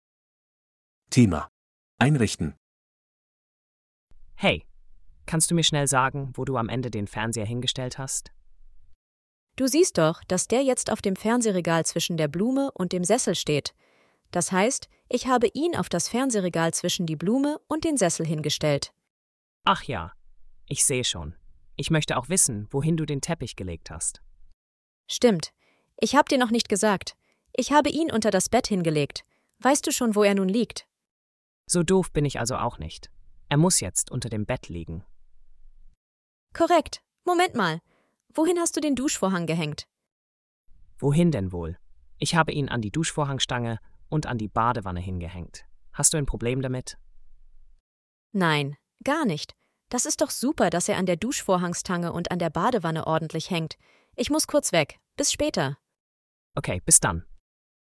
Audio text for the dialogs in task 4: